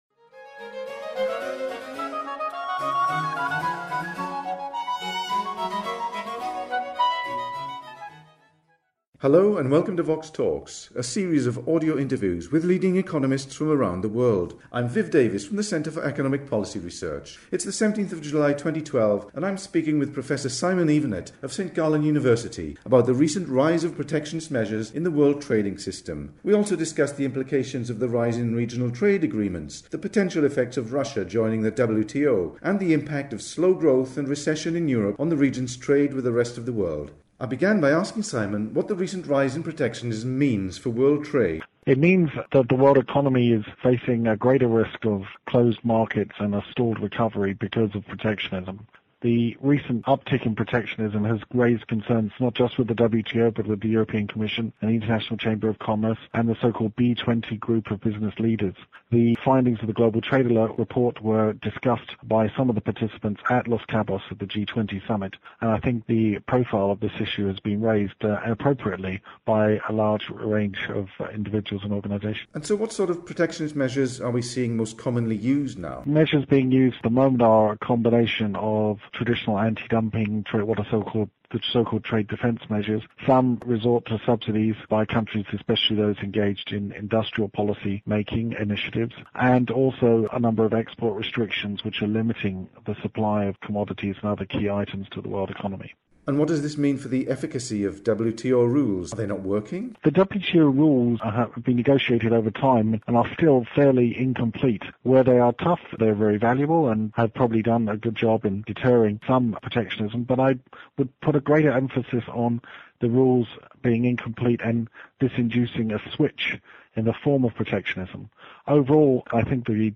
The interview was recorded by telephone on 17 July 2012.